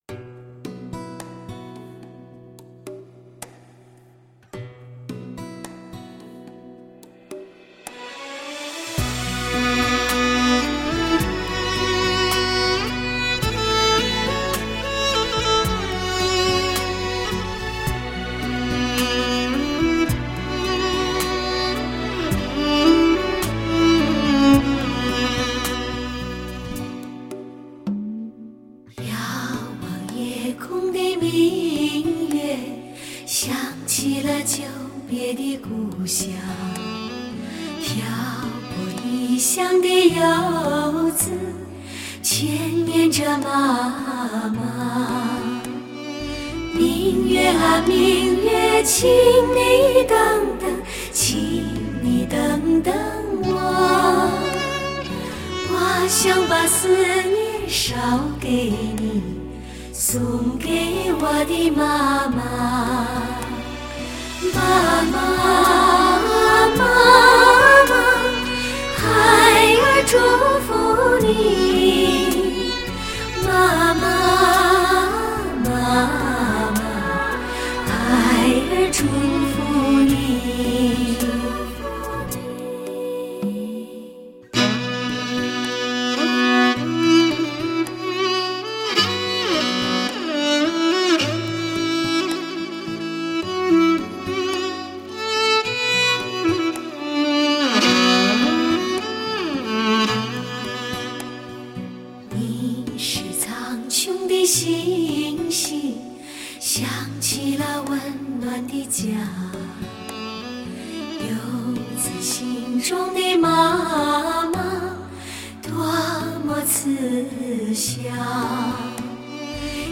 天青如水，随一曲悠扬唱游世界；琴音回响，任千般感触御风而行。